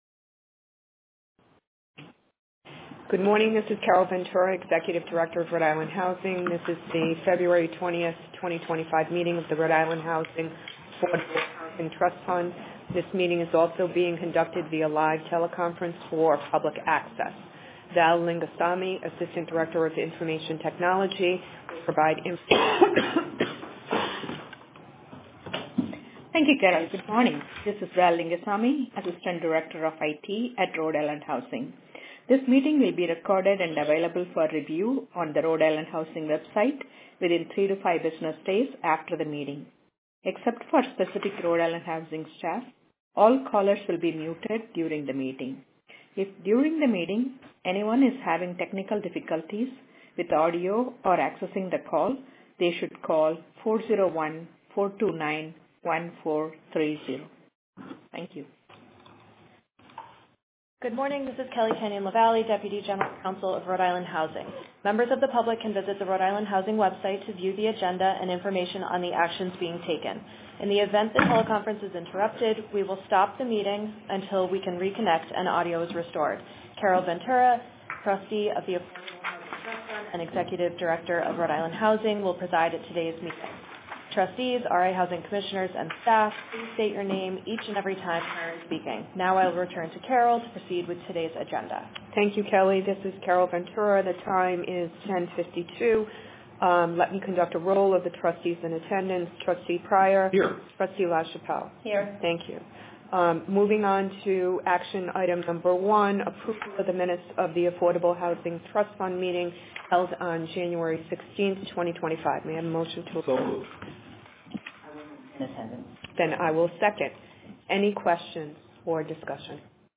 Recording of Rhode Island Housing Development Corporation Board of Directors Meeting: 2.20.25